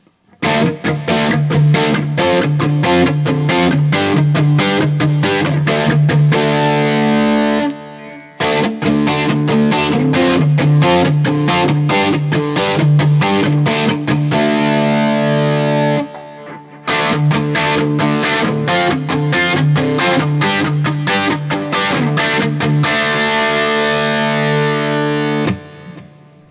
D-Shape Chords
As usual, for these recordings I used my normal Axe-FX Ultra setup through the QSC K12 speaker recorded into my trusty Olympus LS-10.
For each recording I cycle through the neck pickup, both pickups, and finally the bridge pickup.
A guitar like this is really about that semi-hollow sound, and it delivers that in every position and on every fret, though that sort of hollow timbre can obviously get lost when the gain is up high and the effects are set to overwhelming.
Guild-1998-Starfire-4-BL-D.wav